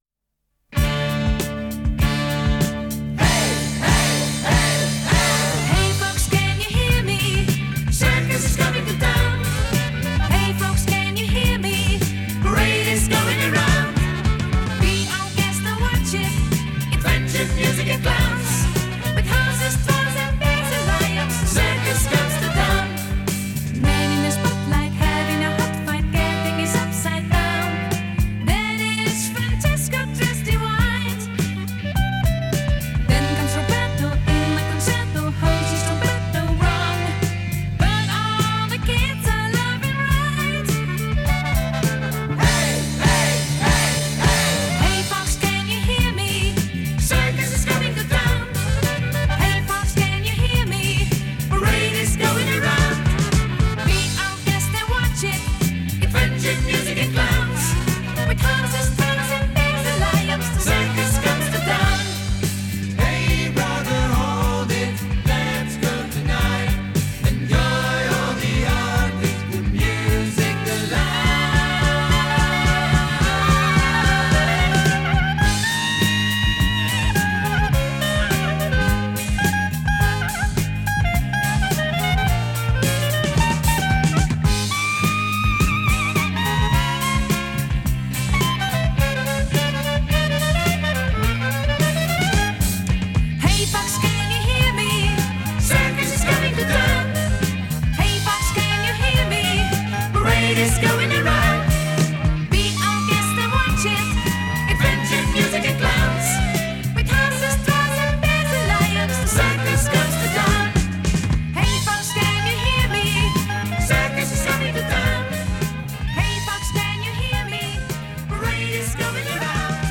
Жанр: Electronic, Rock, Funk / Soul, Pop
Recorded At – Soundpush Studios